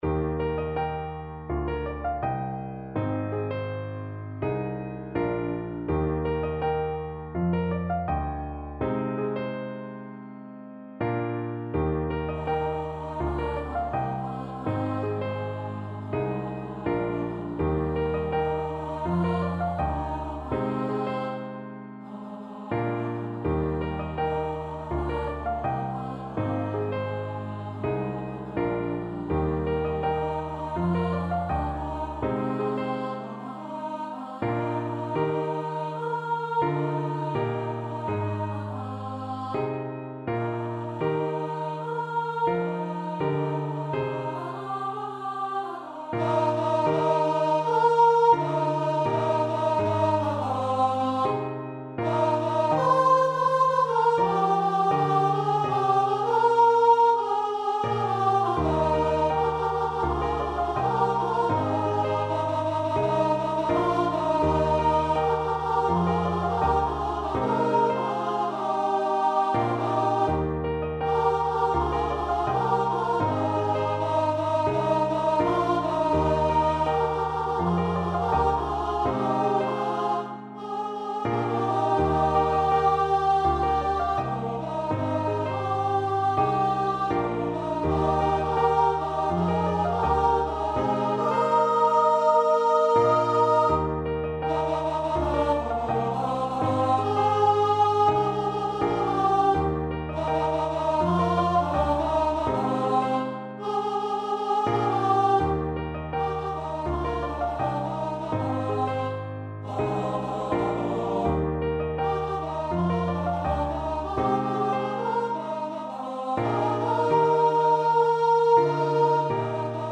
SSA
Transition